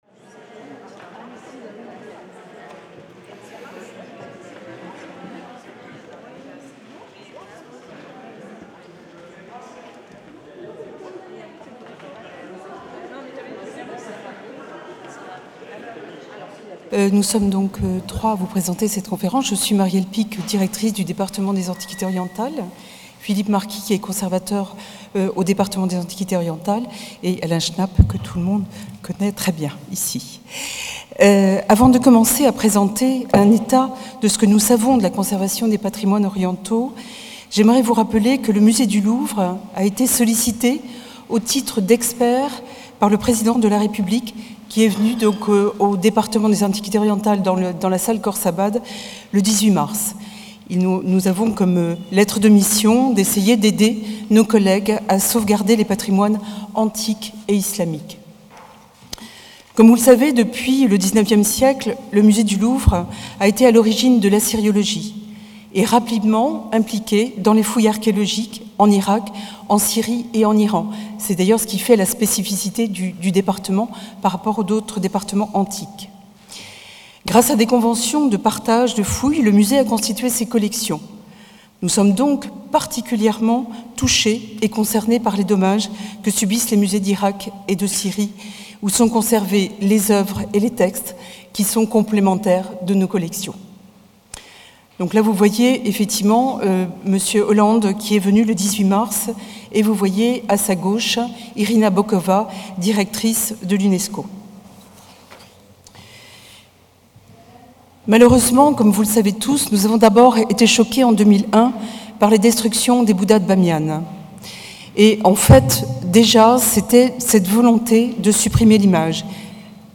Des origines de la notion de patrimoine archéologique au XVIIIe siècle, au destructions actuelles, la table ronde évoquera l’histoire et l’actualité des destructions et de la préservation du passé au Moyen-Orient.